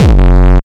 HD BD 14  -R.wav